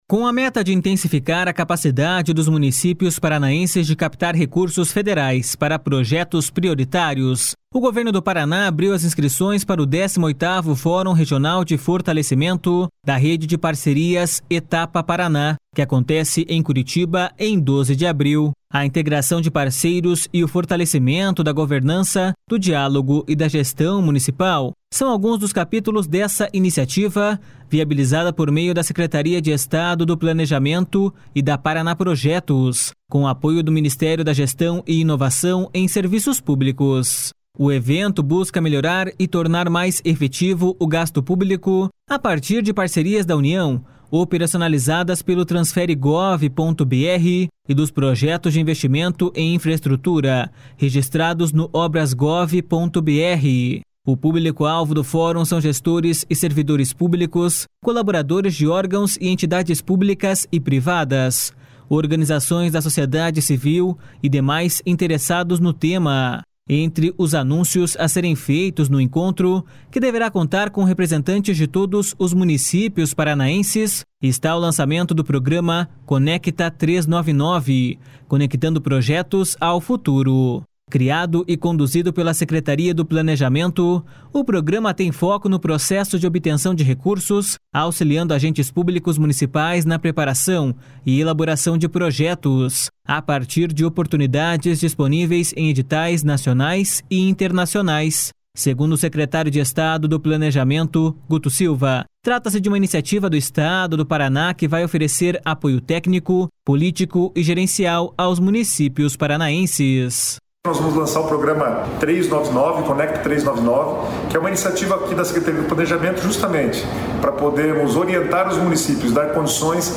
Segundo o secretário de Estado do Planejamento, Guto Silva, trata-se de uma iniciativa do Estado do Paraná que vai oferecer apoio técnico, político e gerencial aos municípios paranaenses.// SONORA GUTO SILVA.//